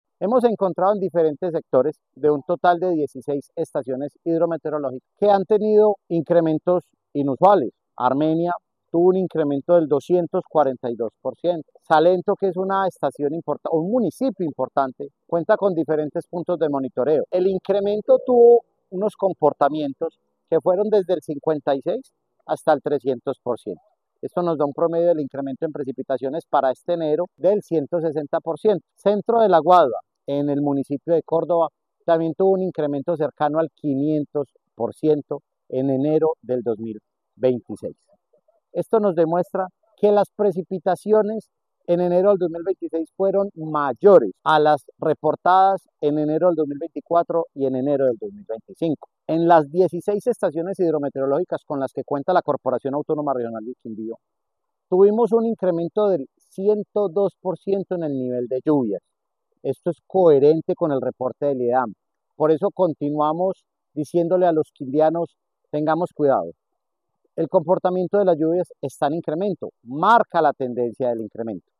Director encargado de la CRQ